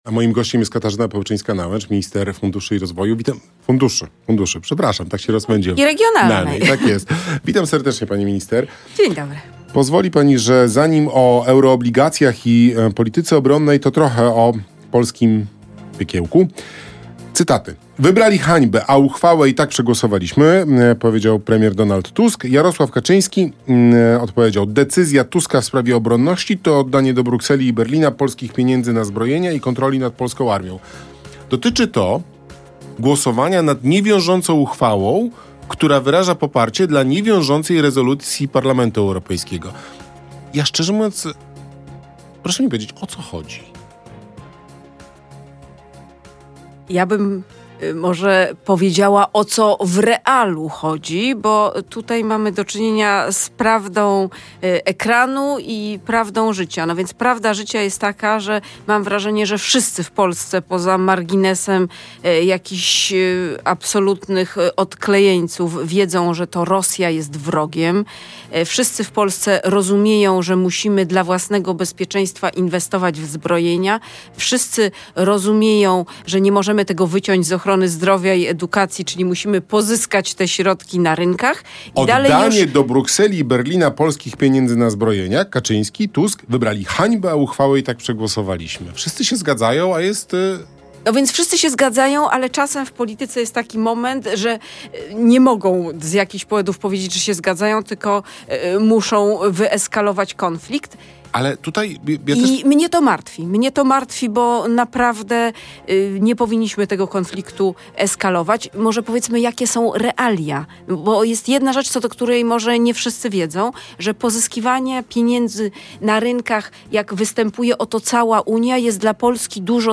Popołudniowa rozmowa w RMF FM to swoiste podsumowanie dnia - komentarz do najważniejszych wiadomości, najbardziej kontrowersyjnych wypowiedzi, spornych decyzji czy dyskusyjnych wyroków. Wywiady w sezonie 2023/2024 prowadzić będą współpracujący z nami dziennikarze